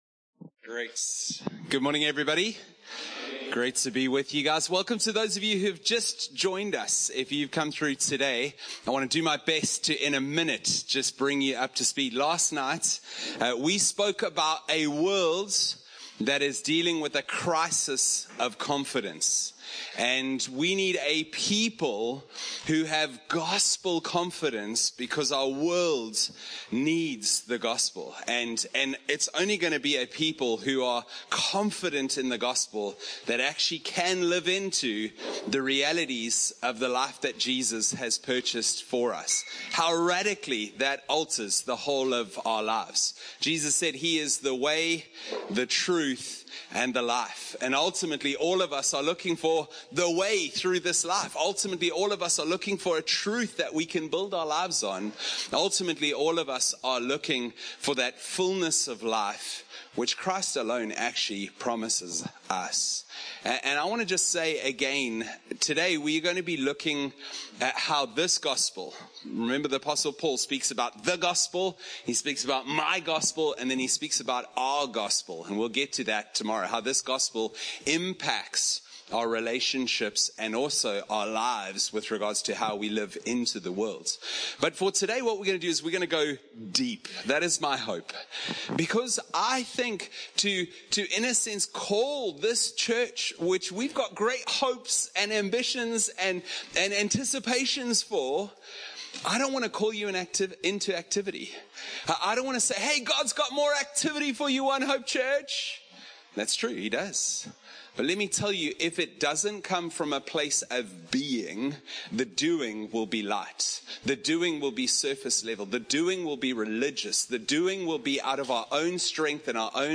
One-Hope-Camp-Sermon-2-Compressed.mp3